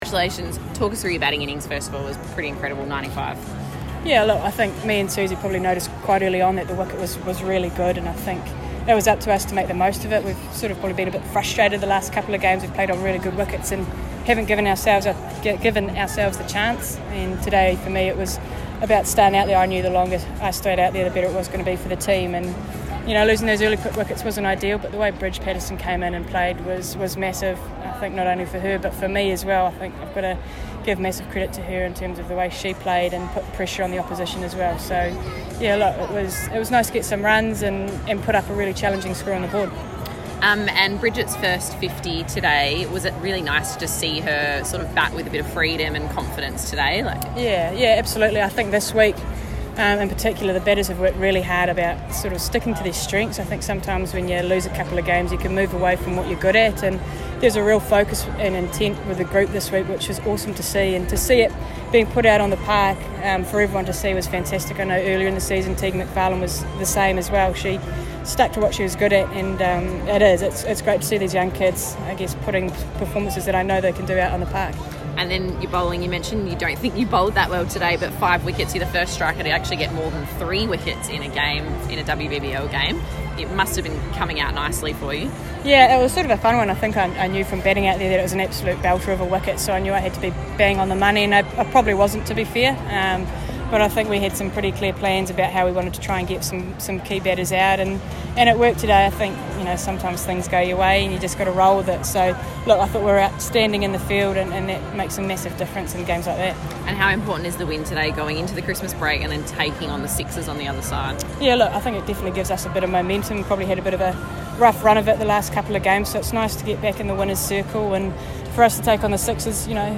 Sophie Devine has led the Adelaide Strikers to a 19-run win at Adelaide Oval , she speaks with the media after the match.